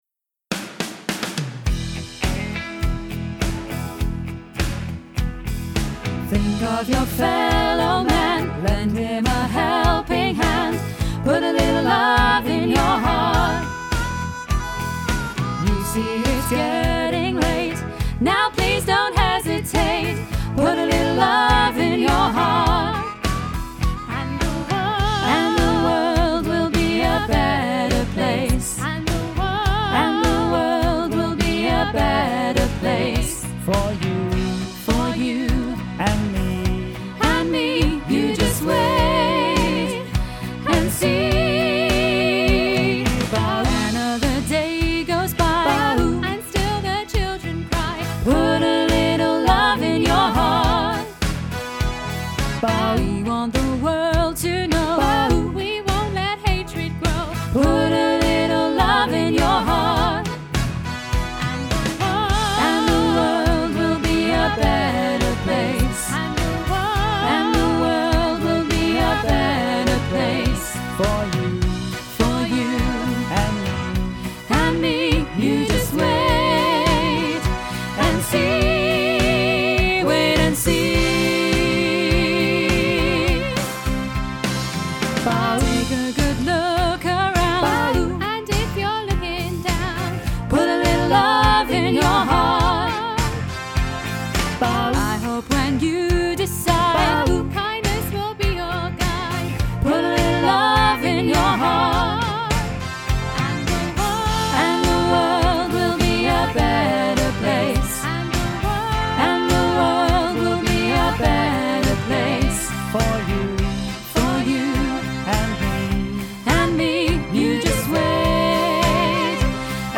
put-a-little-love-alto-half-mix.mp3